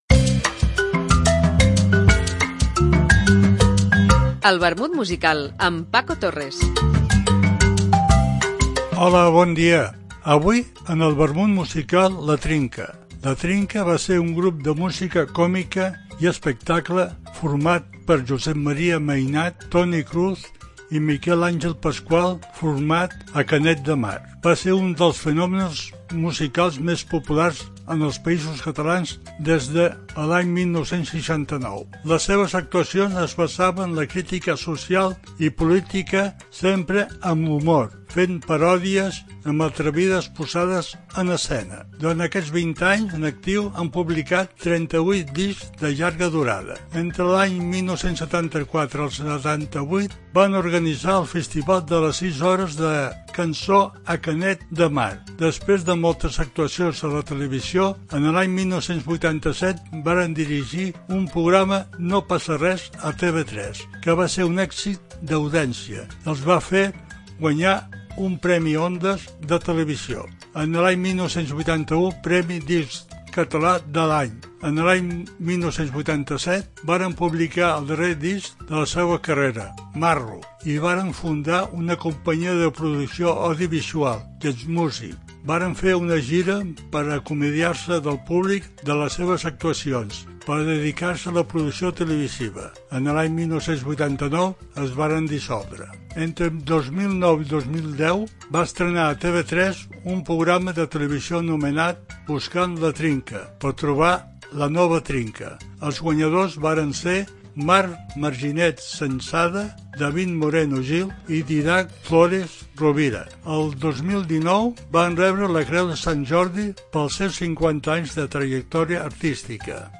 trios...etc. Una apunts biogràfics acompanyats per una cançó.